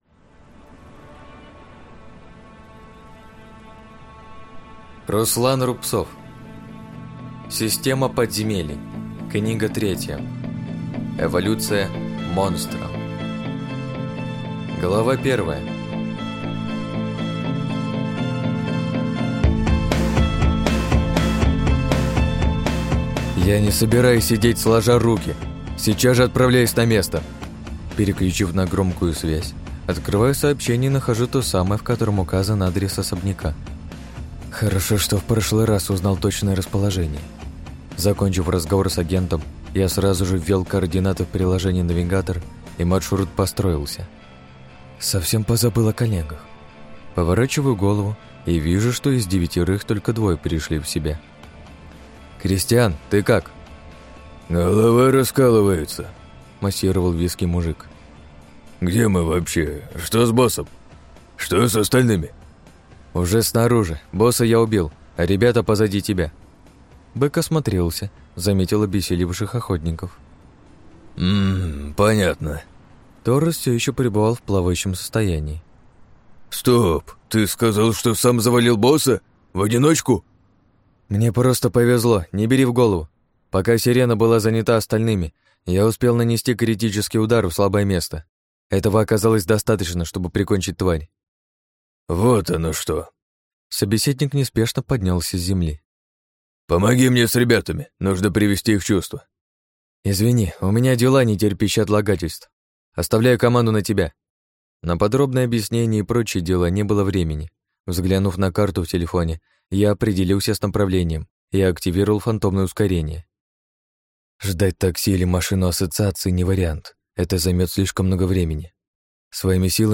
Аудиокнига Эволюция монстра. Книга 3 | Библиотека аудиокниг